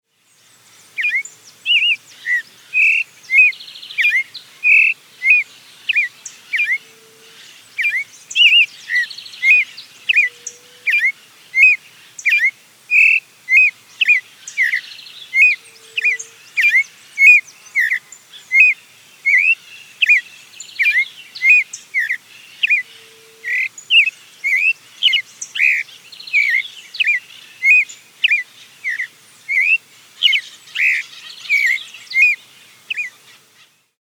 Sabiá-poca
O sabiá-poca tem este nome inspirado em uma de suas vozes, um chamado curto e discreto que parece dizer "poca".
É uma ave de canto belíssimo e que aprecia bastante os frutinhos da aroeira, árvore comum em ambientes de restinga e presente em algumas áreas da Ilha do Bom Jesus.
Nome Científico: Turdus amaurochalinus
Aprecie o canto do